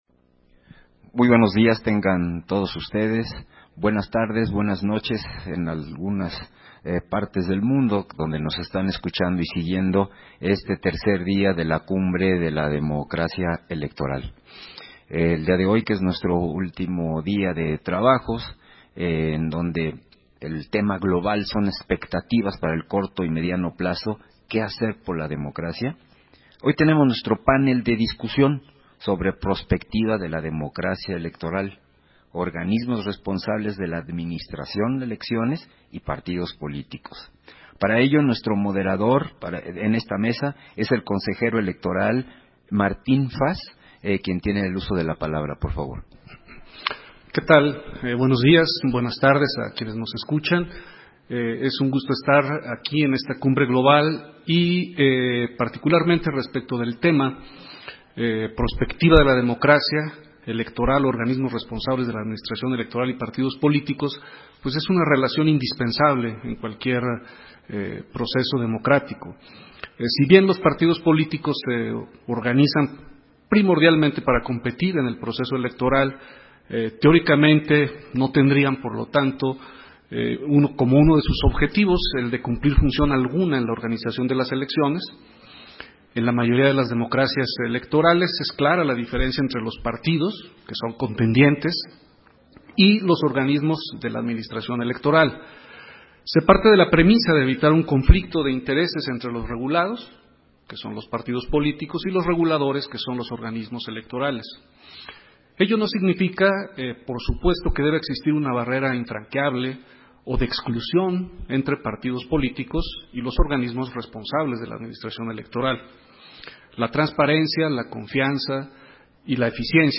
Versión estenográfica del panel: Prospectiva de la democracia Electoral. Organismos responsables de la administración electoral y partidos políticos, en el marco del tercer día de la Cumbre Global de la Democracia Electoral